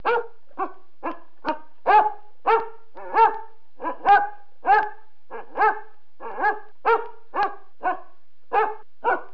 دانلود صدای حیوانات جنگلی 45 از ساعد نیوز با لینک مستقیم و کیفیت بالا
جلوه های صوتی